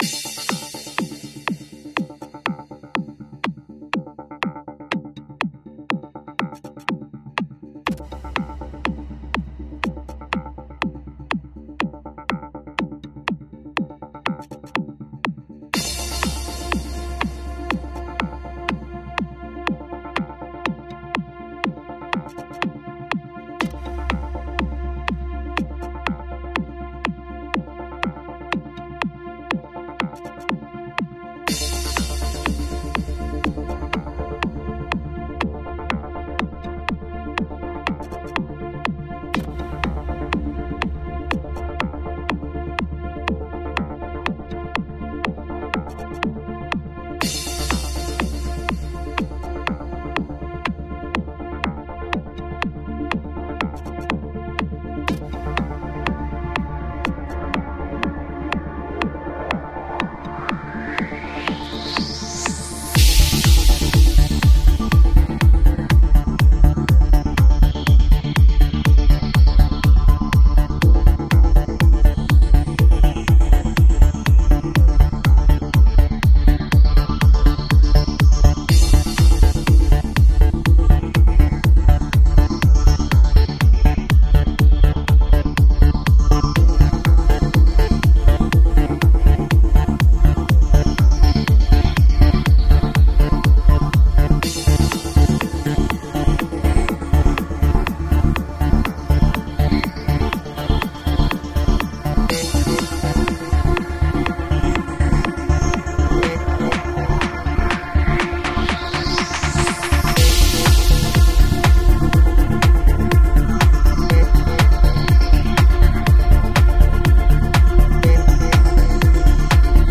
Genre: Progressive House